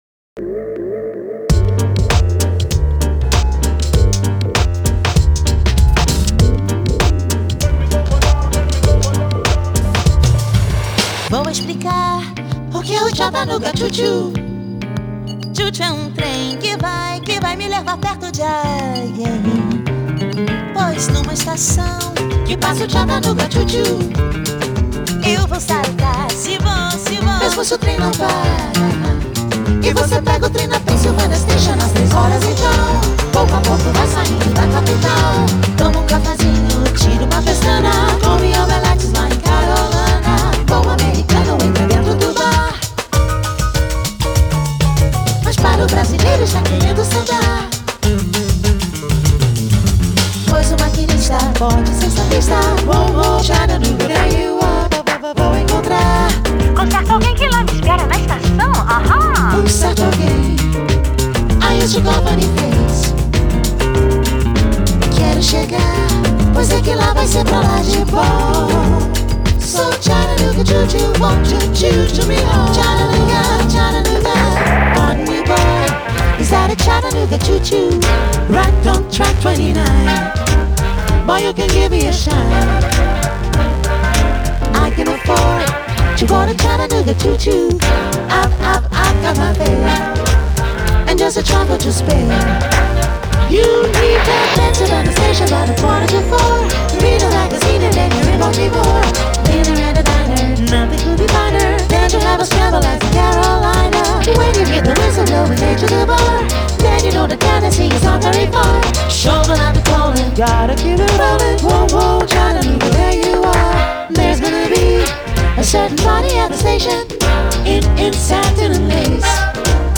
Genre: Blues, Vocals